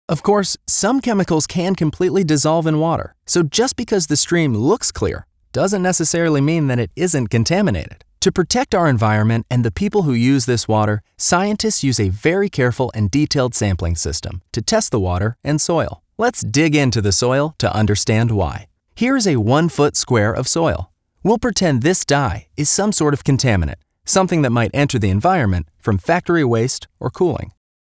englisch (us)
Sprechprobe: eLearning (Muttersprache):